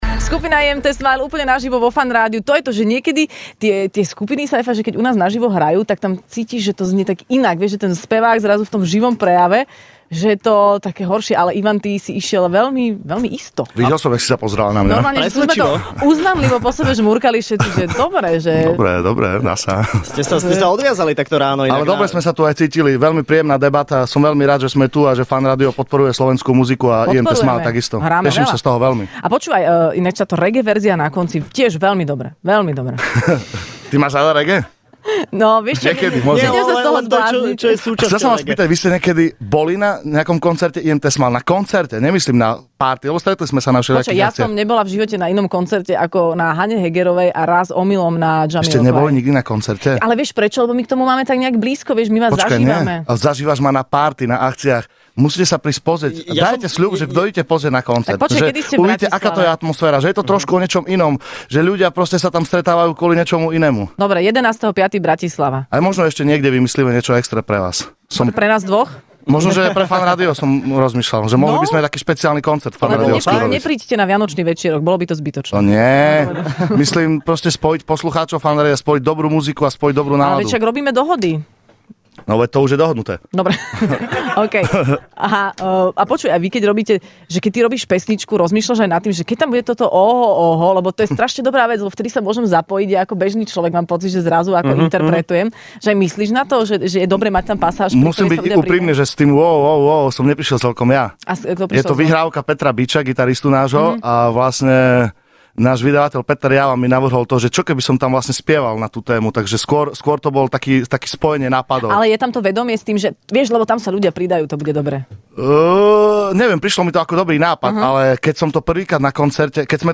Hosťom v Rannej šou bola dnes skupina IMT Smile